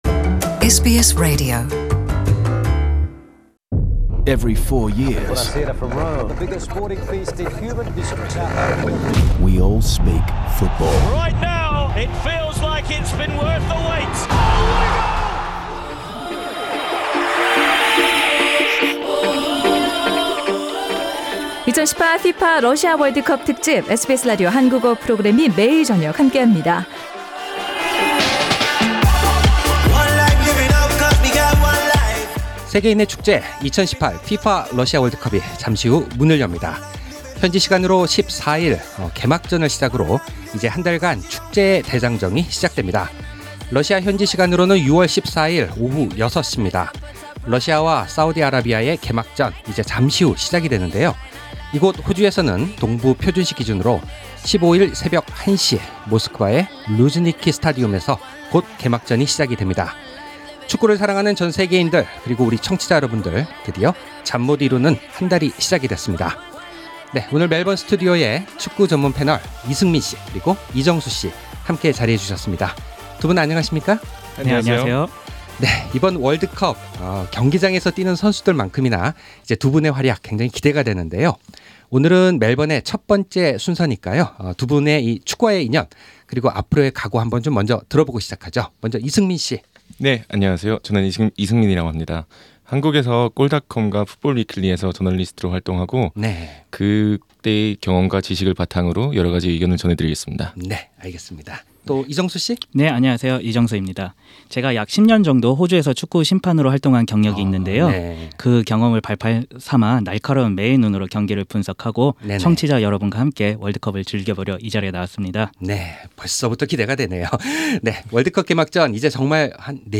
SBS Korean Program’s the 2018 FIFA World Cup Russia Special Series launch with a panel of five soccer experts.